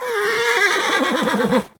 CosmicRageSounds / ogg / general / combat / creatures / horse / he / taunt1.ogg